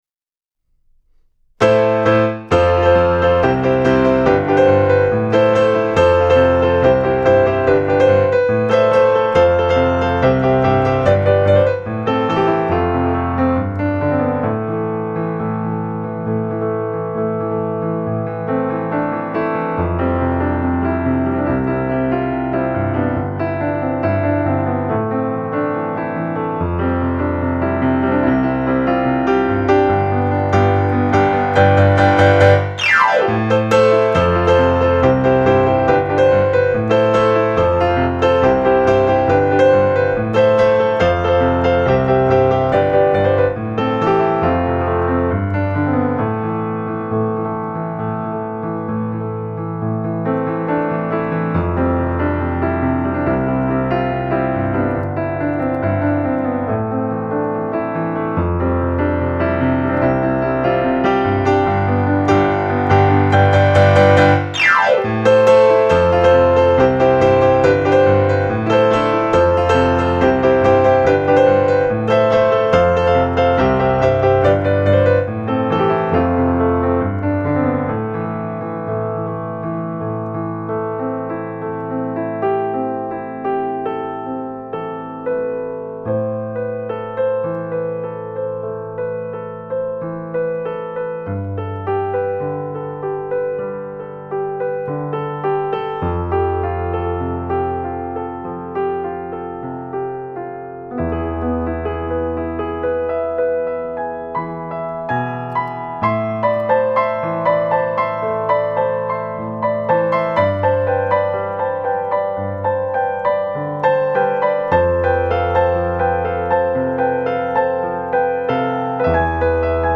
Overflow – 2014. Fast, loud, and a lot of fun.